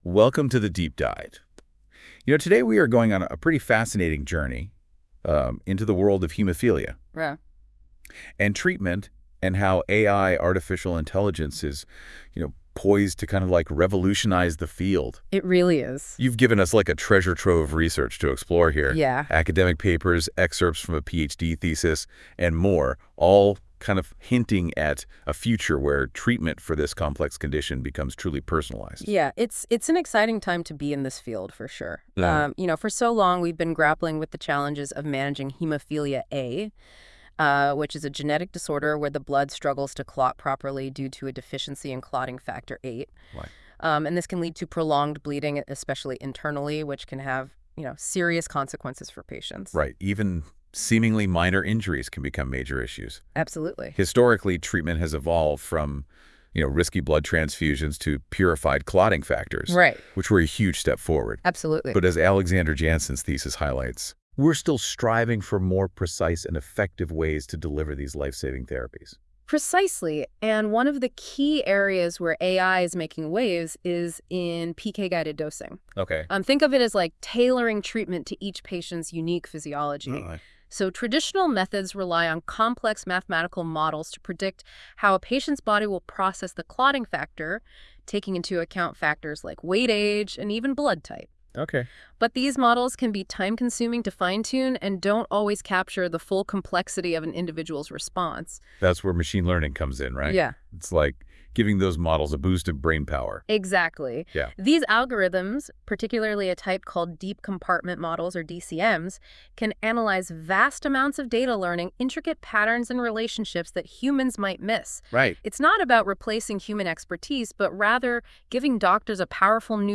With AI a podcast is generated automatically from the thesis.